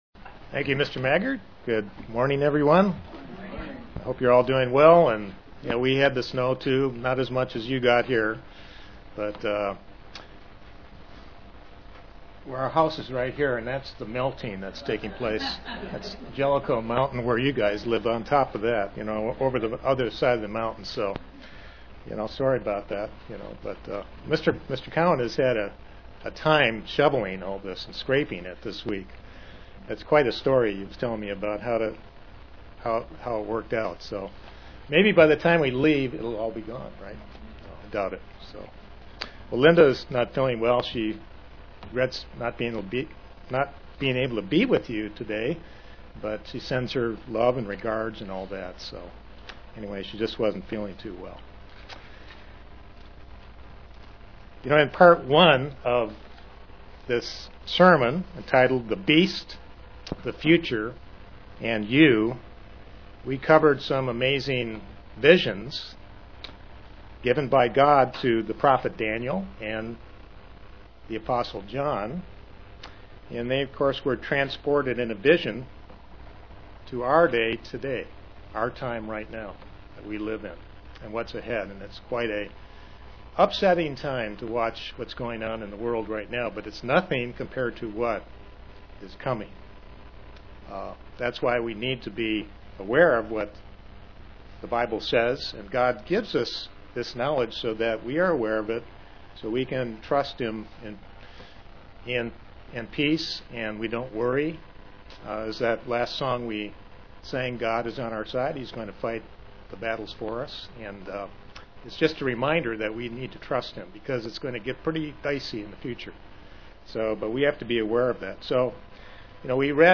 As we head toward the prophecies concerning the end time, it is important for each Christian to consider their lives and how to prepare for the future. This sermon is the conclusion of what is expected of a Christian as we head toward the future. (Presented to the London KY, Church)